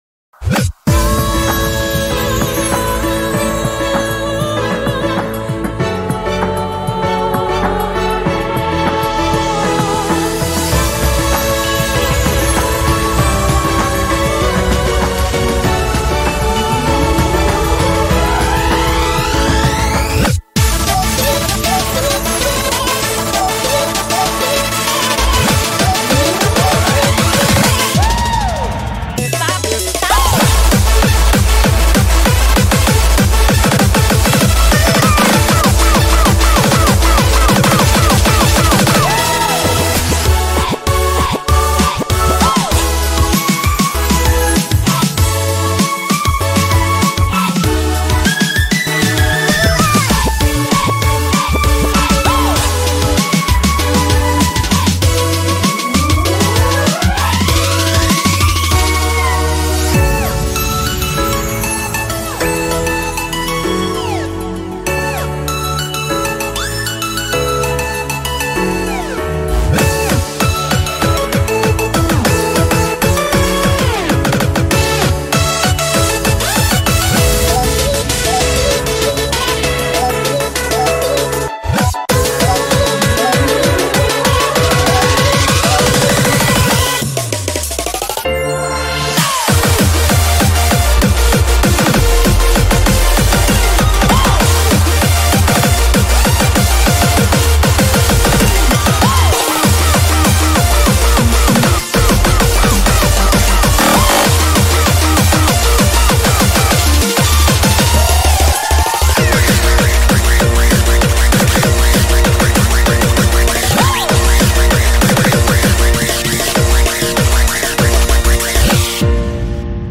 BPM195
Audio QualityPerfect (Low Quality)